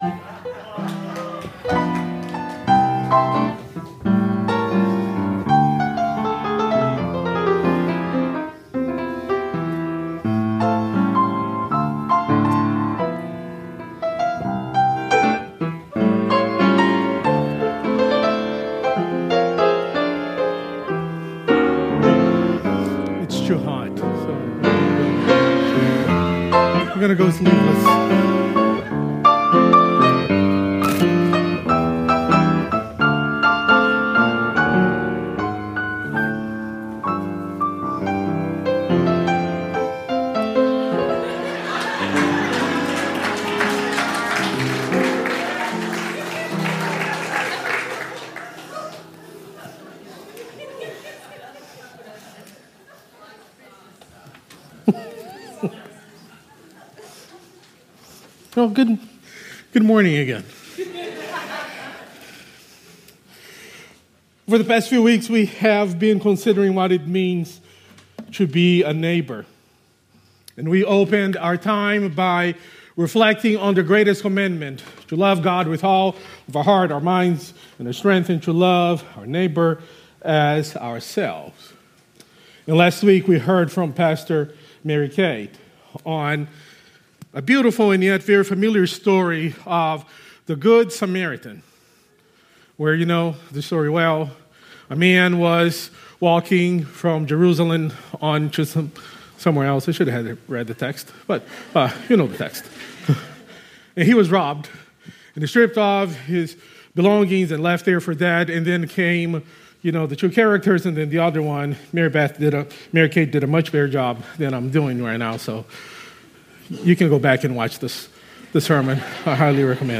Sermons | Hilldale United Methodist Church